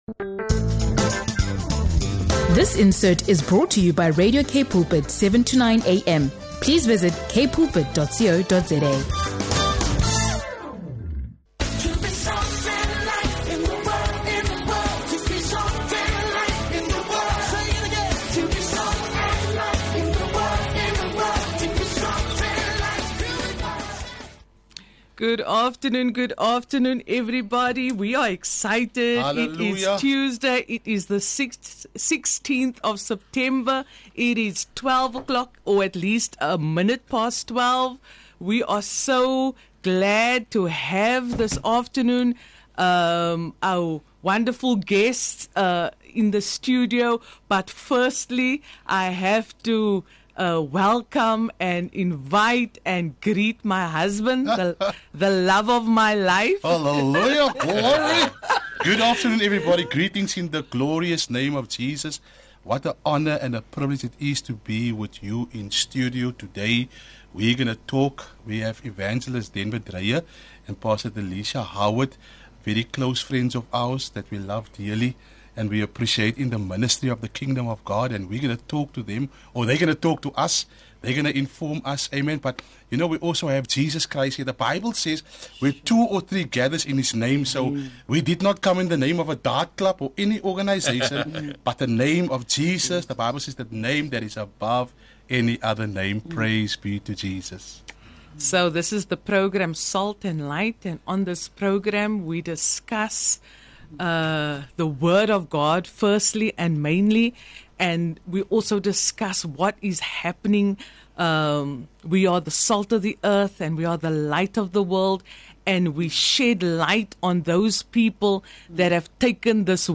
Together with your hosts, they dive into pressing spiritual and social issues—from drug-related violence and gang syndicates to the church’s role in modern-day warfare against the kingdom of darkness.